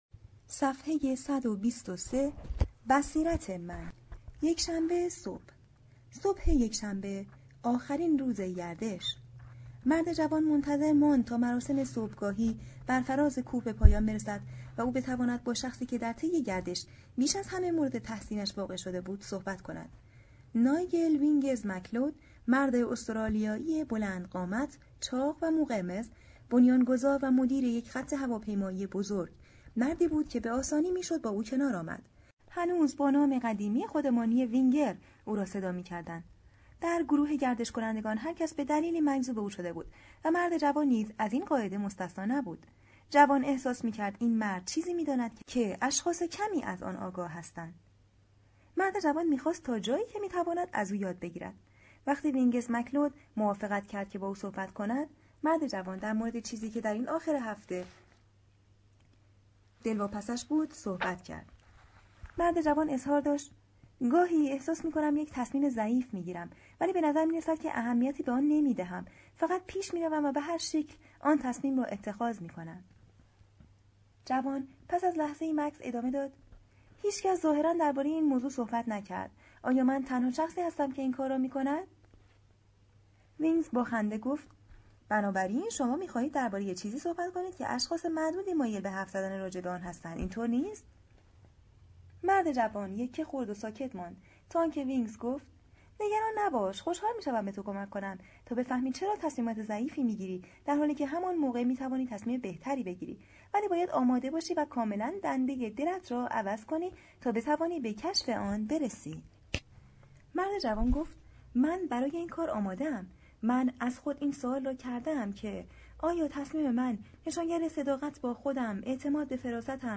کتاب صوتی راهنمای تصمیم گیری بهتر از اسپنسر جانسون